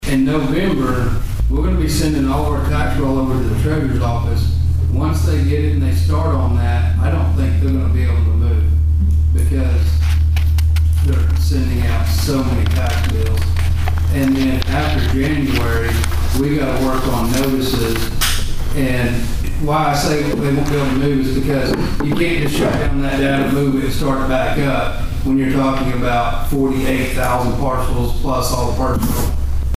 Assessor Ed Quinton Jr. hopes it doesn't get pushed back anymore